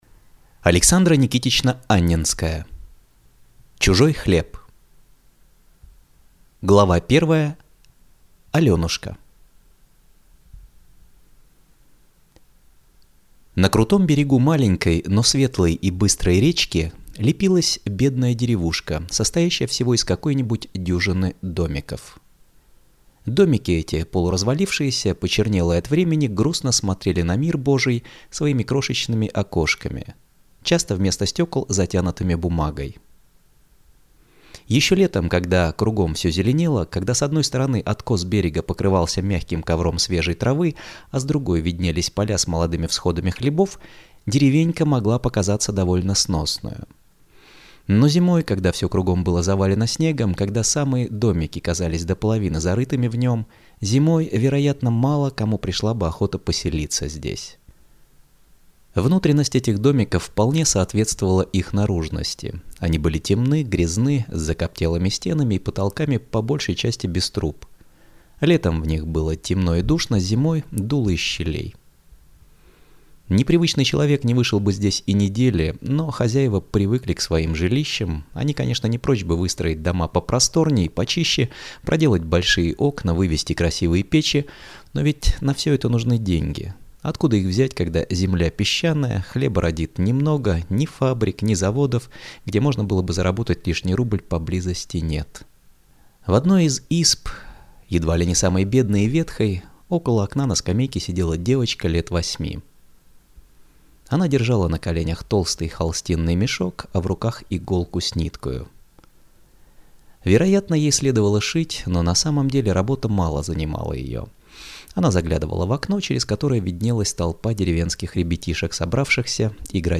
Аудиокнига Чужой хлеб | Библиотека аудиокниг